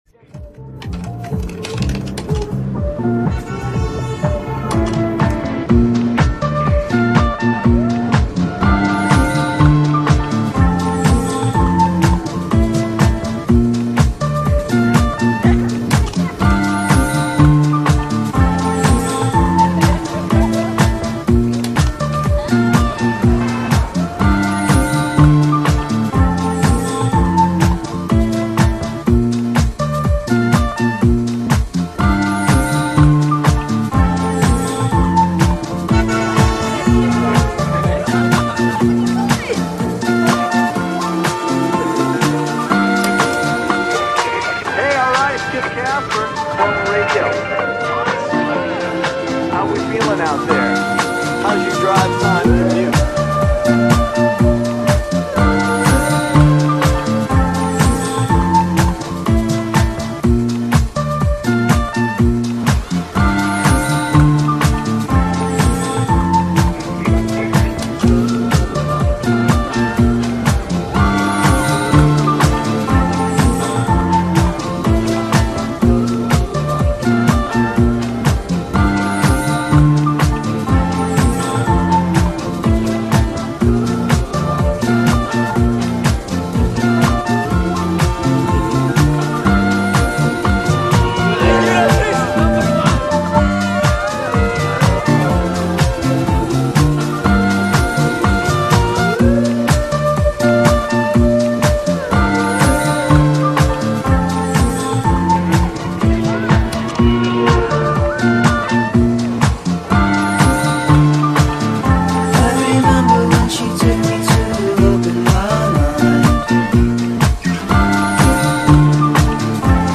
Io qui mi sono emozionato con questi pochi clac, stac, fzzz.